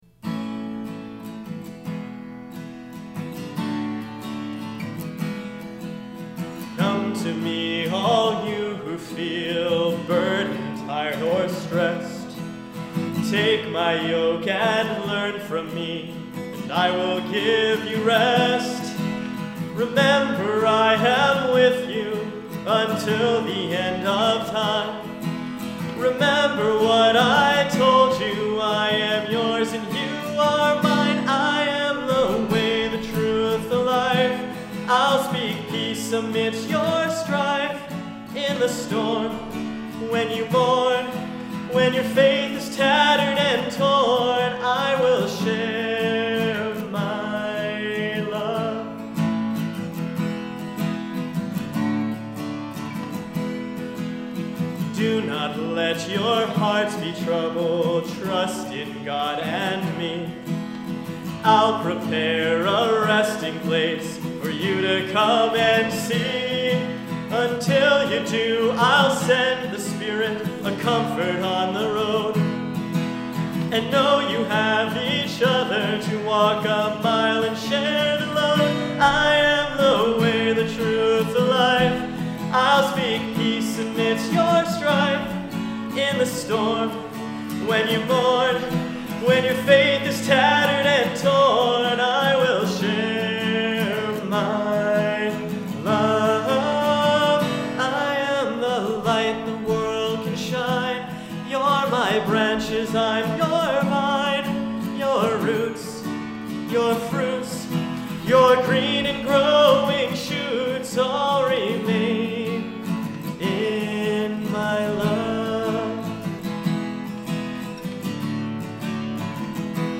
New Songs (recorded live during YouTube Morning Prayer services)
i-am-live.mp3